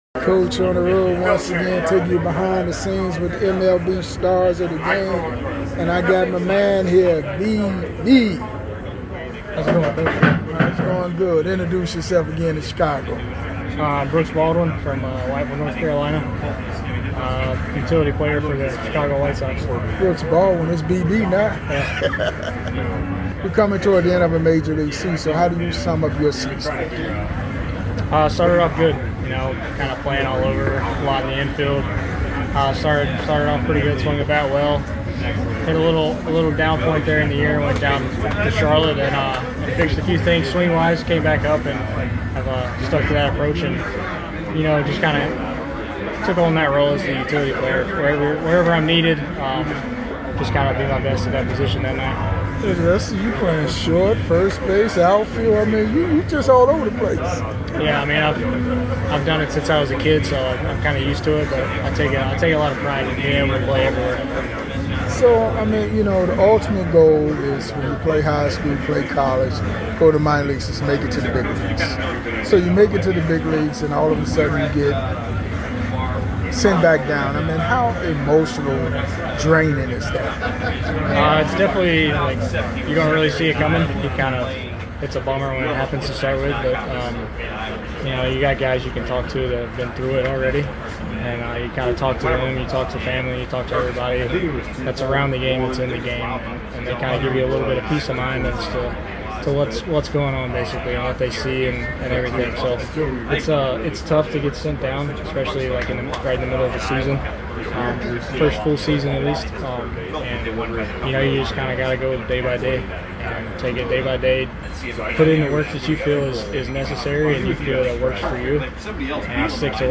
Classic MLB Interviews with the stars of the games